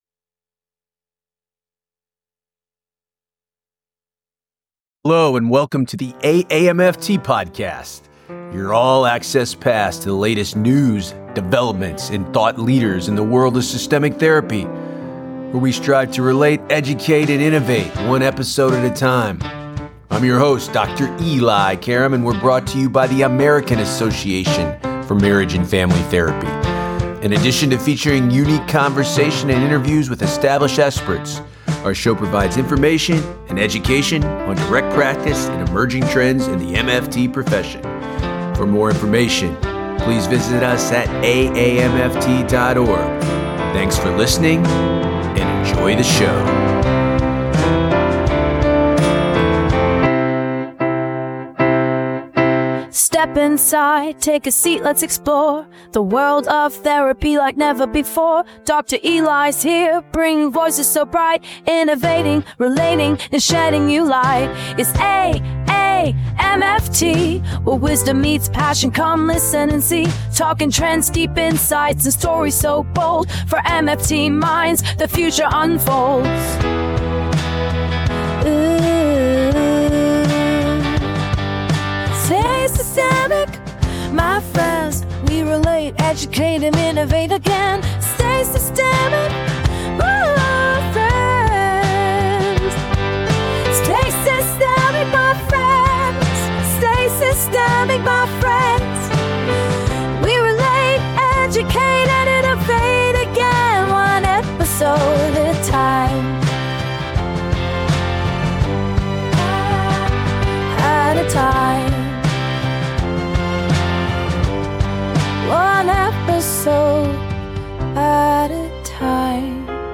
The episodes explore topics that relationship-based therapists care about, and features unique conversations and interviews with established experts. The show provides information and education on direct practice and emerging trends in the MFT profession.